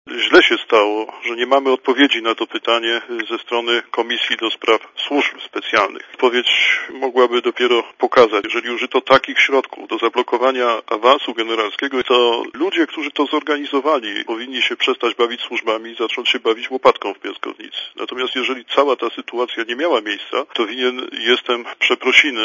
Całą sprawę z zablokowaniem mojej nominacji generalskiej powinna wyjaśnić sejmowa komisja do spraw służb specjalnych - powiedział Radiu Zet wiceszef Agencji Bezpieczeństwa Wewnętrznego Mieczysław Tarnowski.
Mówi pułkownik Tarnowski (87 KB)